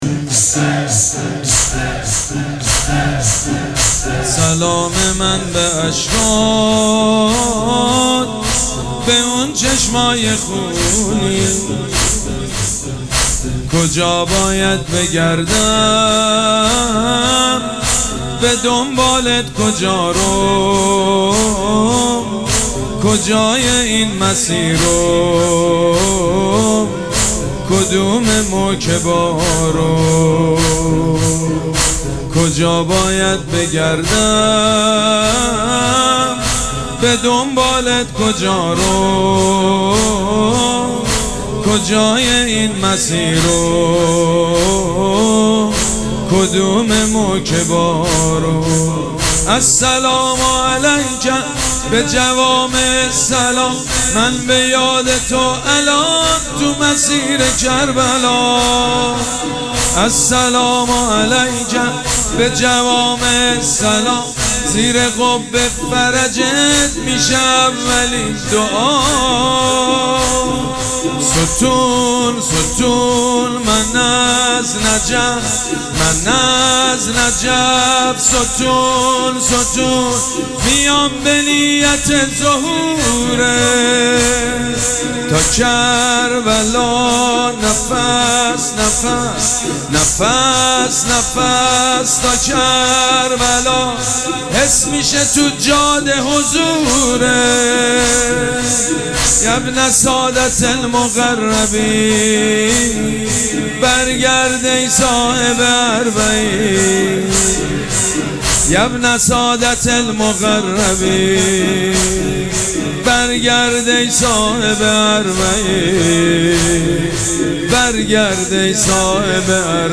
شب دوم مراسم عزاداری اربعین حسینی ۱۴۴۷
مداح
حاج سید مجید بنی فاطمه